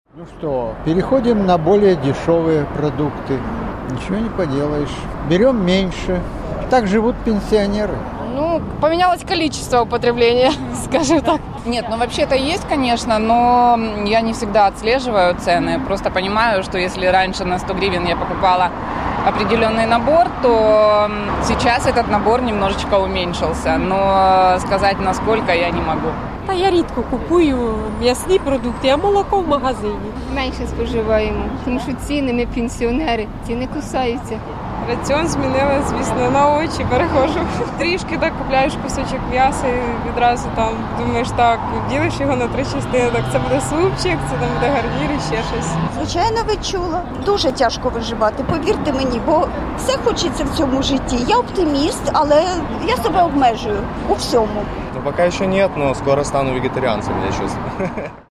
Між тим, опитані Радіо Свобода кияни в абсолютній більшості розповідають, що вже відчули зростання цін на продукти: